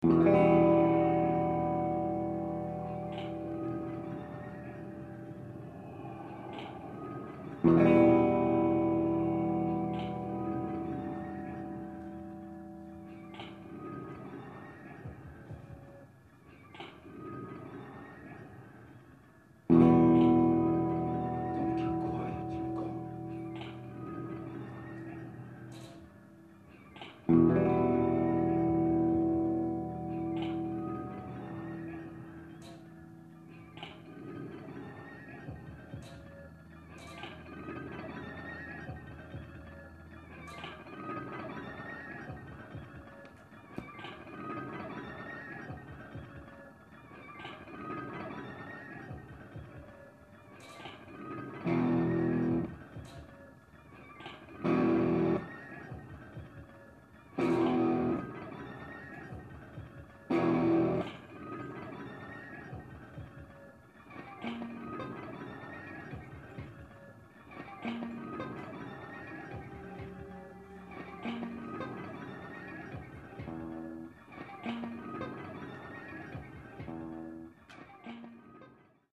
Ça commence par des souffles lointains saisis en boucle
chant
puis montent des petits cliquouillis de guitare
machines